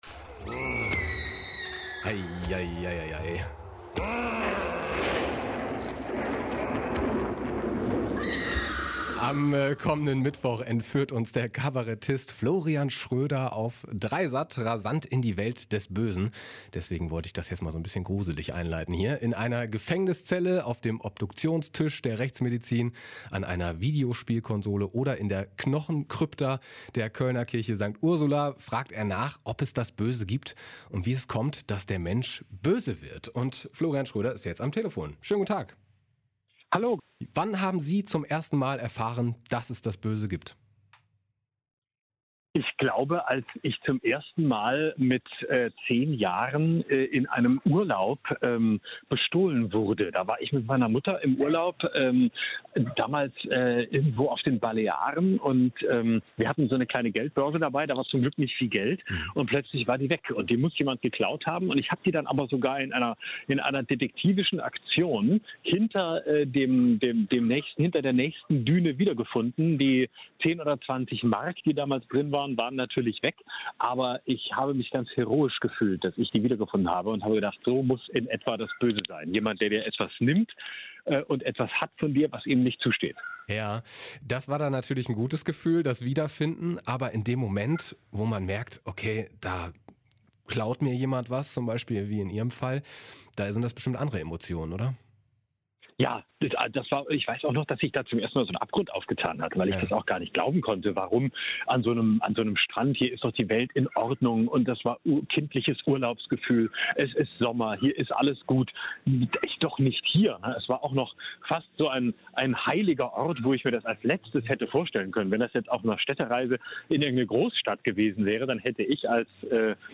Ein Interview mit Florian Schroeder (Kabarettist)